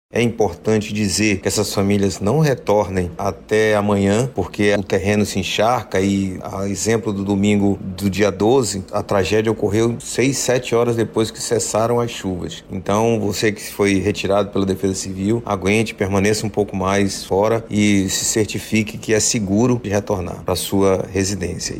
O titular da Secretaria Municipal de Segurança Pública e Defesa Social – Semseg, Sérgio Fontes, faz um alerta.